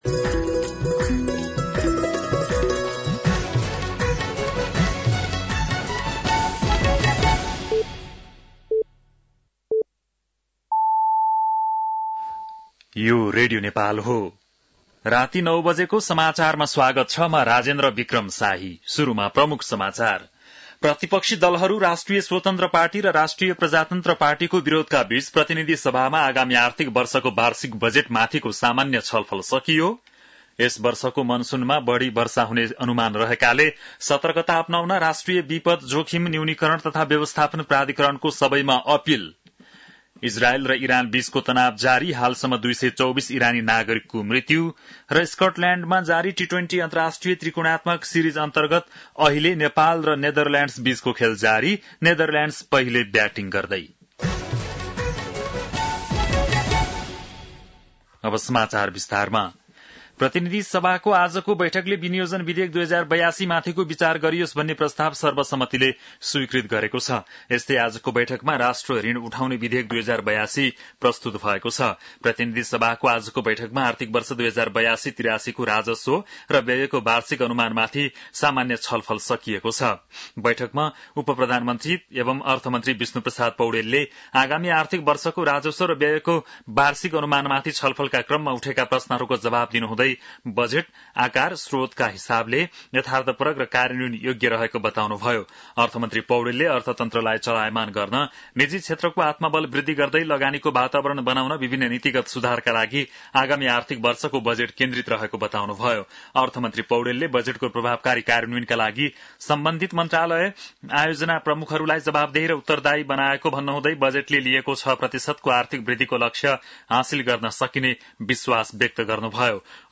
बेलुकी ९ बजेको नेपाली समाचार : २ असार , २०८२